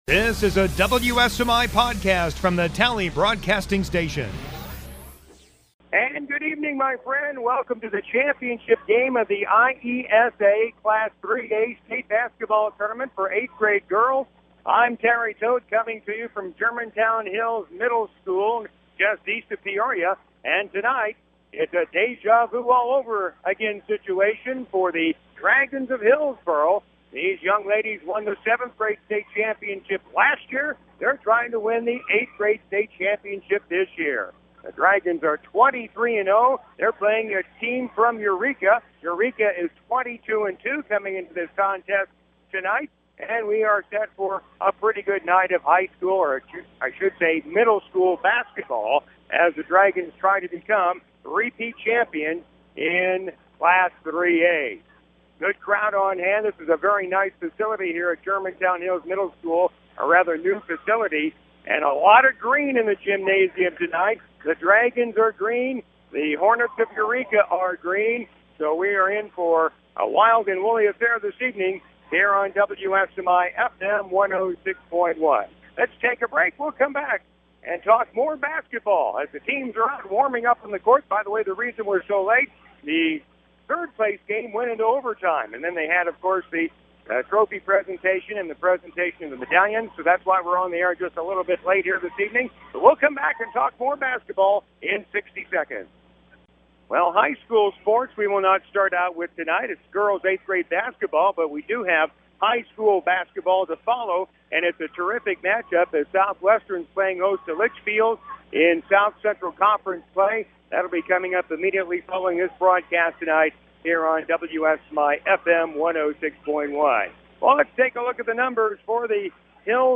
12/18/2014 Girls 8th Grade State Championship Game Hillsboro Dragons vs Eureka